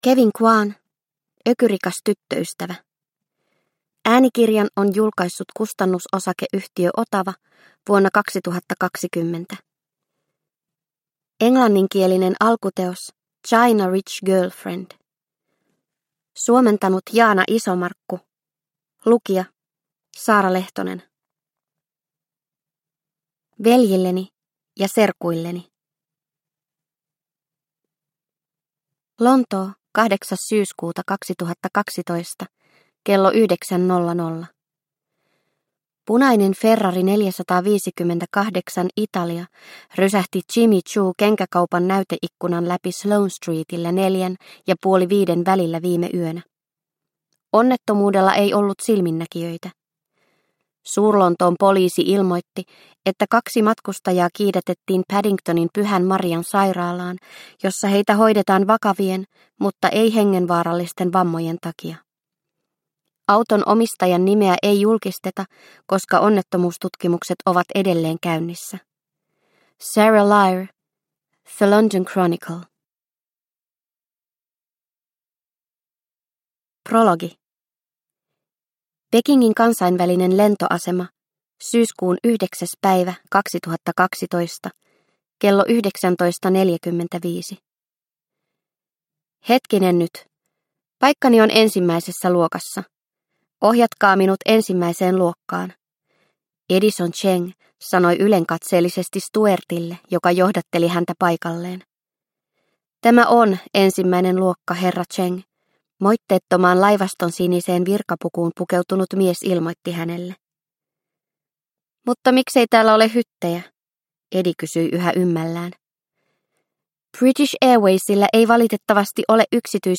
Ökyrikas tyttöystävä – Ljudbok – Laddas ner